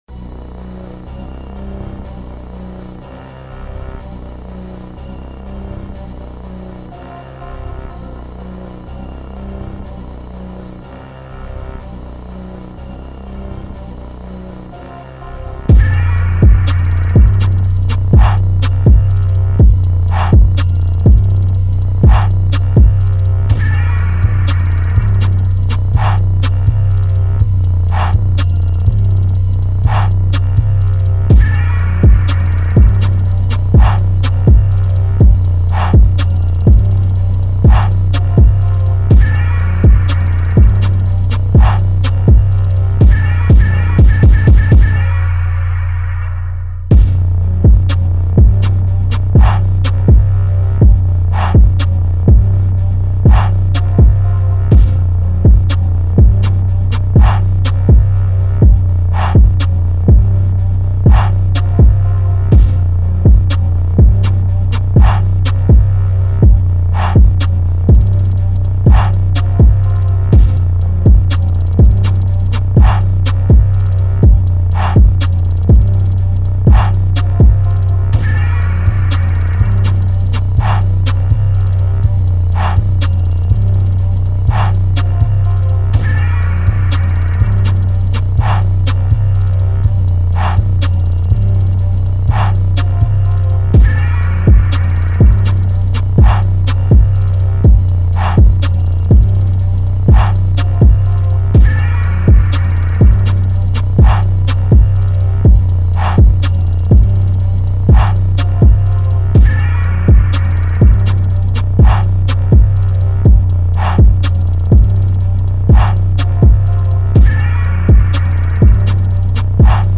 This is the official instrumental
Trap Instrumental